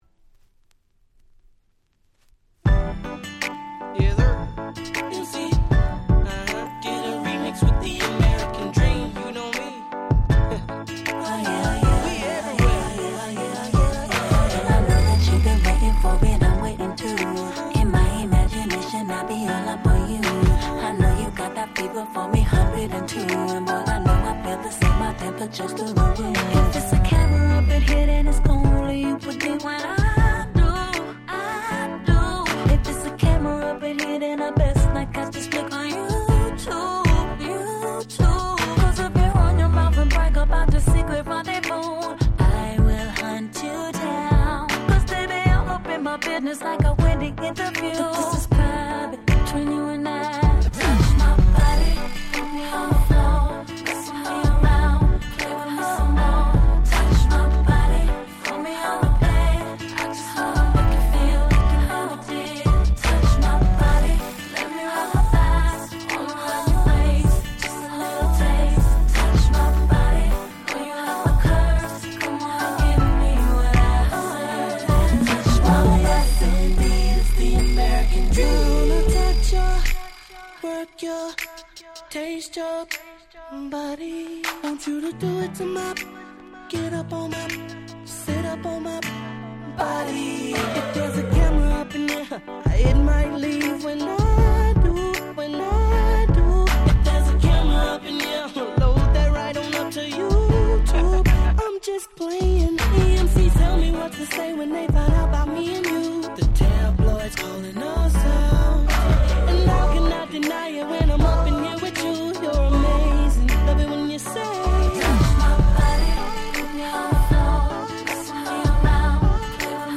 08' Super Hit R&B !!